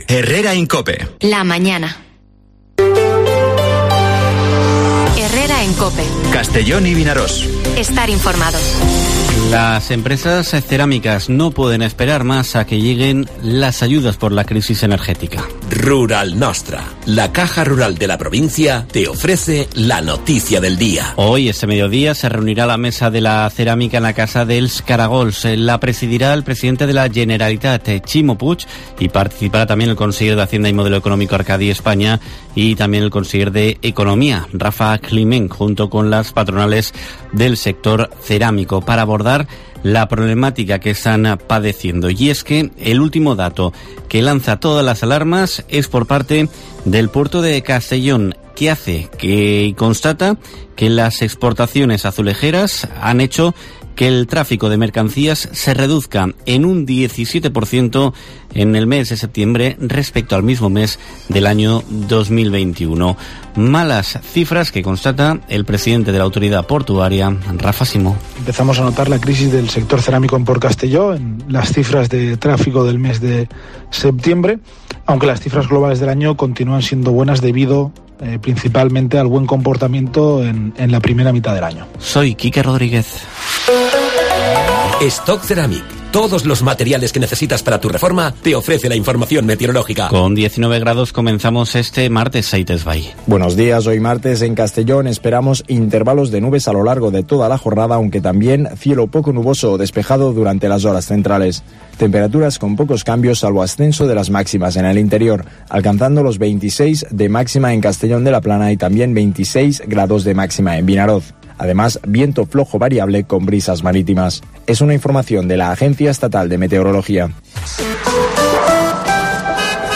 Informativo Herrera en COPE en la provincia de Castellón (18/10/2022)